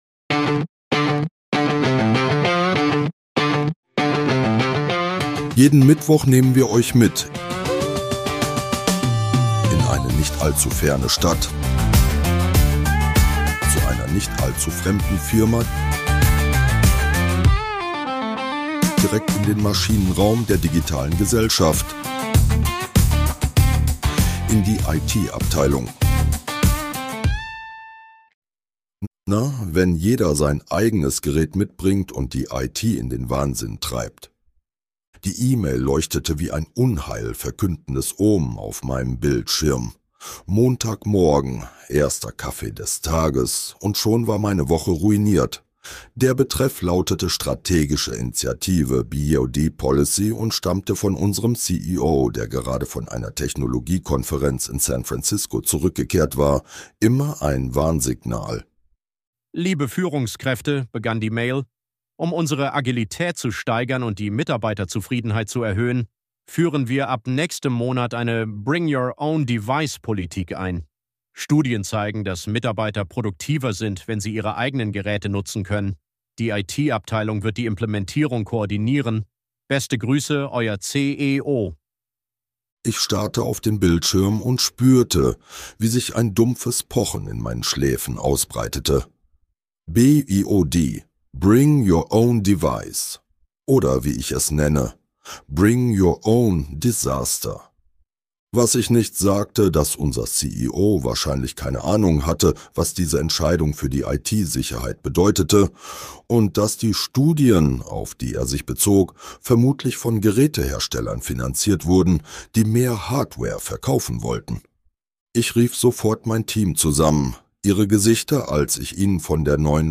Dieser Podcast ist Comedy.
(AI generiert) Mehr